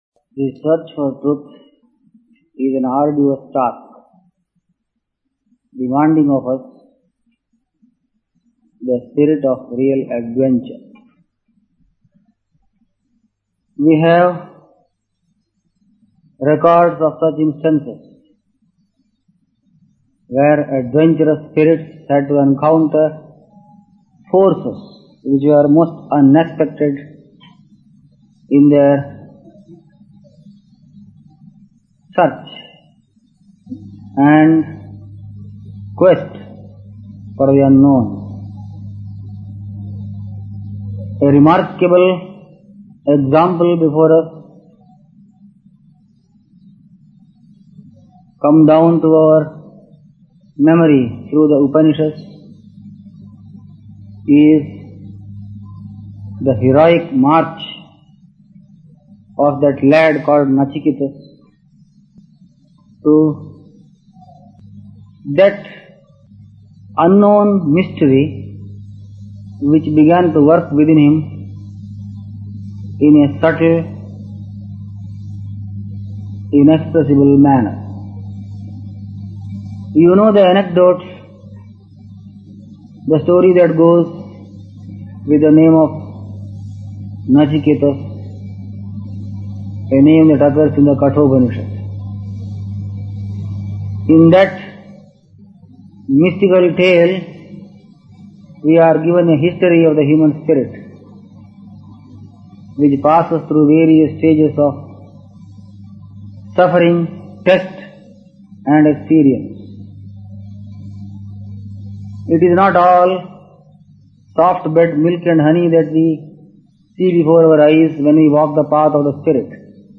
Discourse on the Kathopanishad given on June 19, 1972